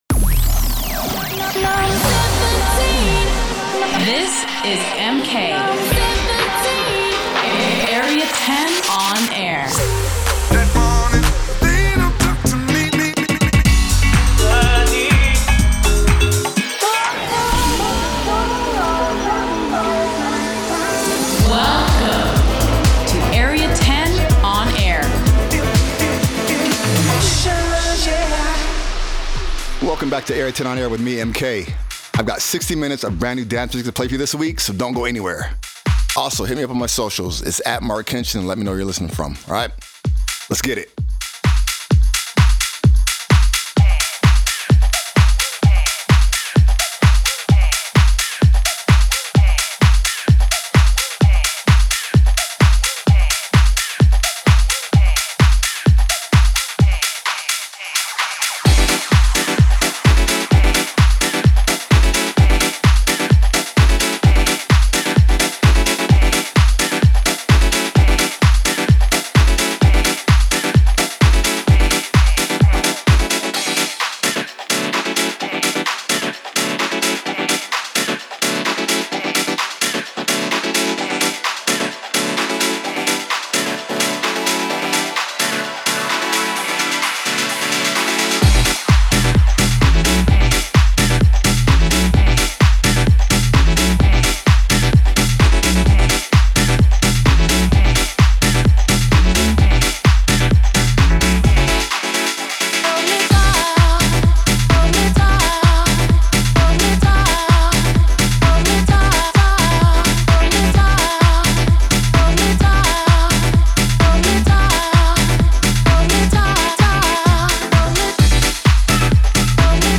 Delving in to house and techno, new and old
Guest Mix